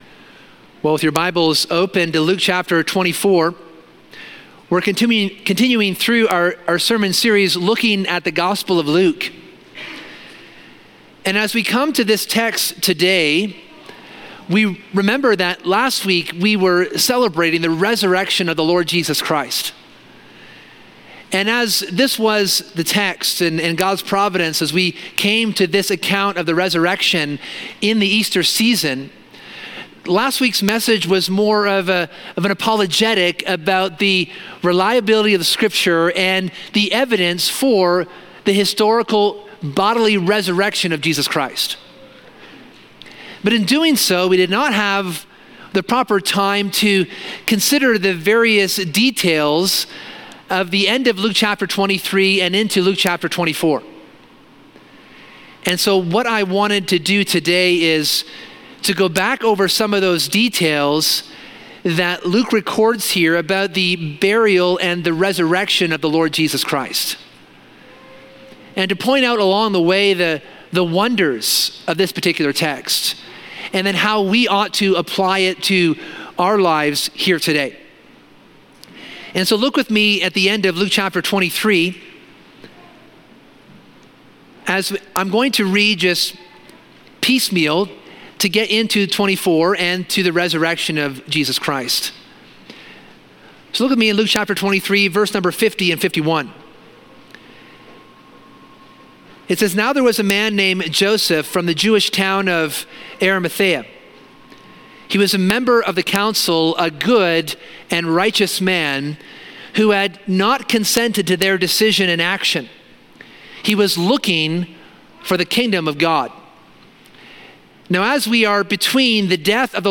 This sermon revists the resurrection account, considering the acts of Joseph of Arimathea, the witness of the angels, and the response of the women and disciples of Jesus.